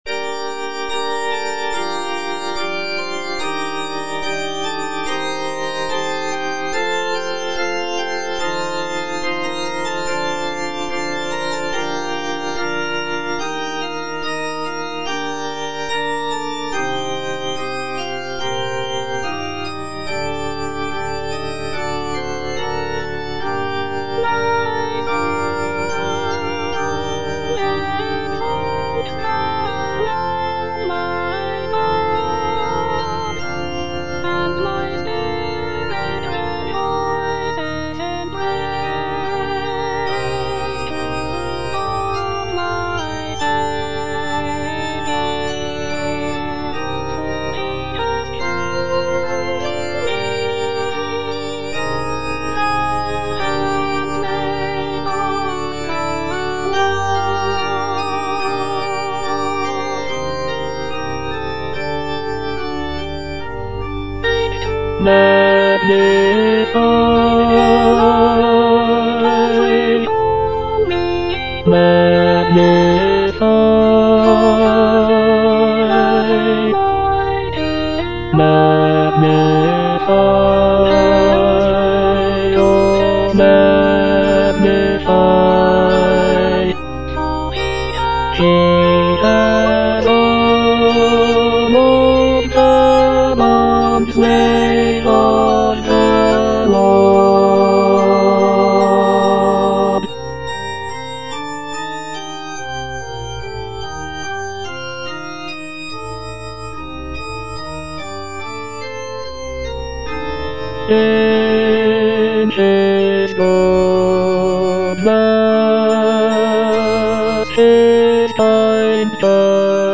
Bass (Emphasised voice and other voices)